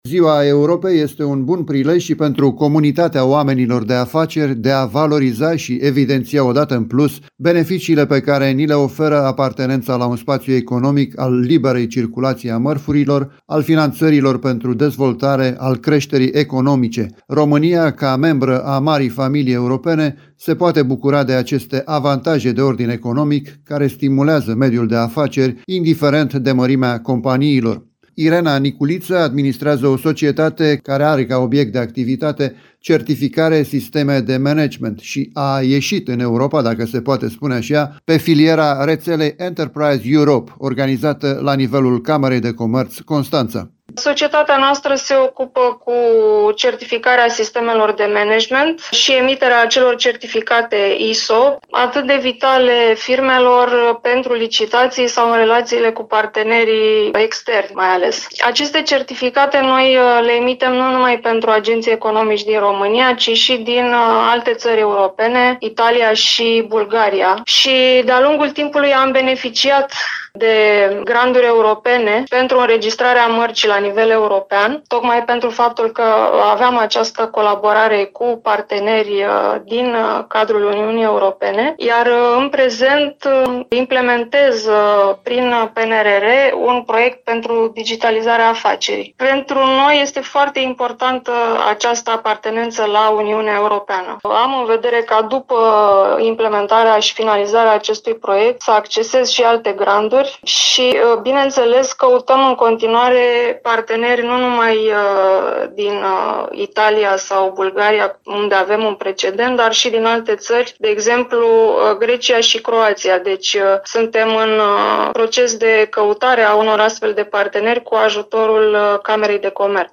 Camera de Comerț Constanța a dedicat Zilei Europei un eveniment pentru a marca acest  parteneriat cu o comunitate economică de succes.